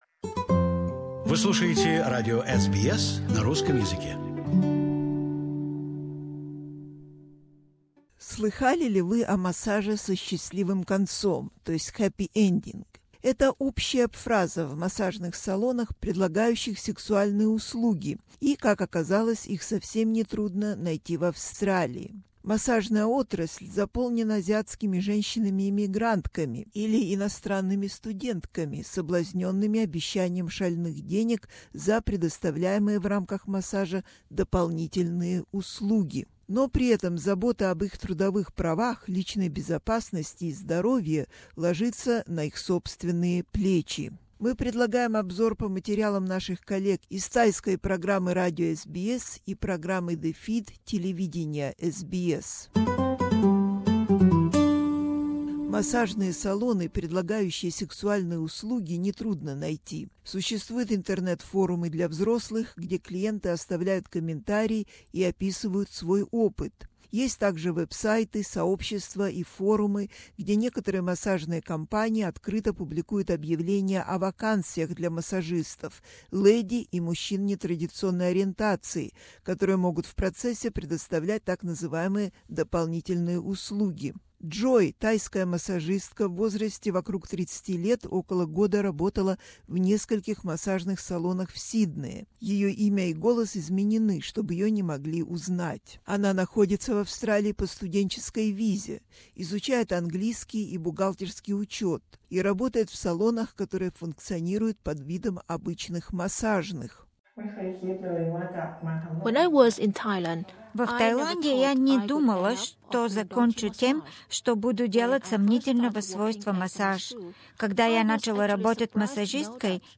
But they are often left to fend for themselves, in terms of their workplace rights, safety and health. ... has this special report, compiled by SBS Radio's Thai language program and SBS Television's The Feed.